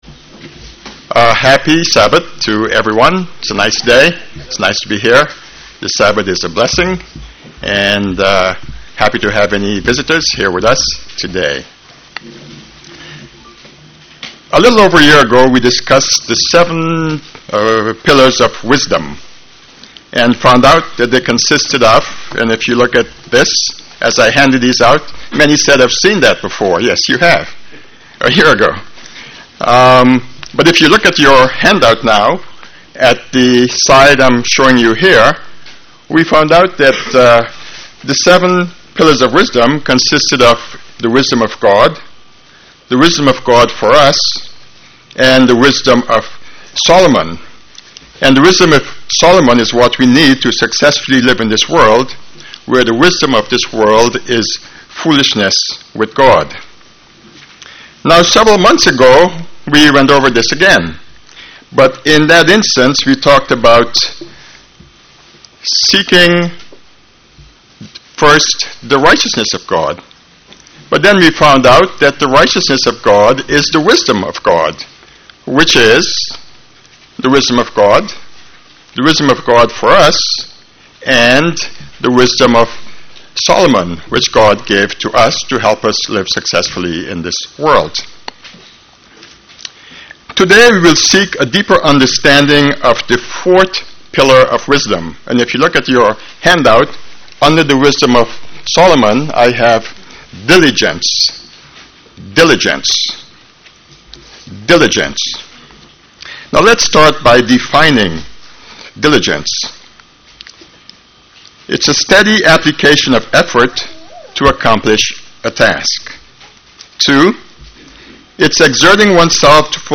UCG Sermon Studying the bible?
Given in St. Petersburg, FL